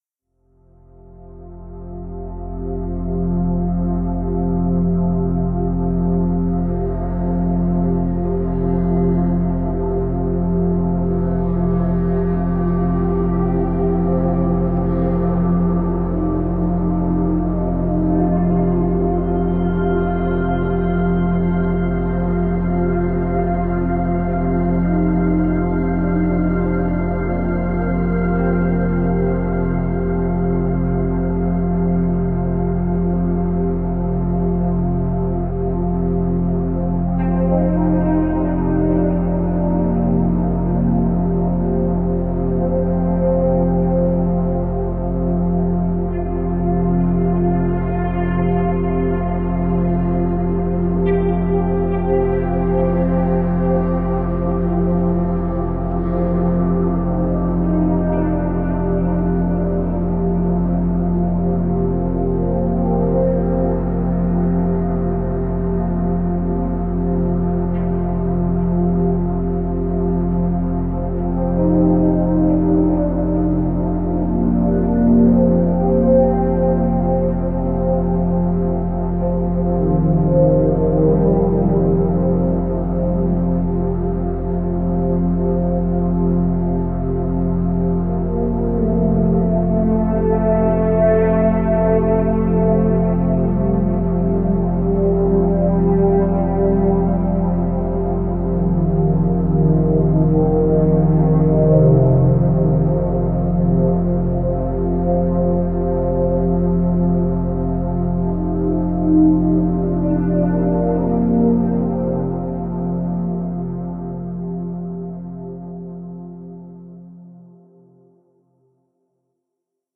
174Hz – فرکانس 174 هرتز
در این فصل می‌خوایم براتون یک سری موسیقی با فرکانس‌های مختلف قرار بدیم که بهشون تون هم می‌گن.
به بعضی از این ها اصطلاحا می‌گن Solfeggio Frequencies که ترجمش فرکانس‌های سلفژی میشه که به فرکانس‌های خاصی اتلاق میشه.
174Hz.mp3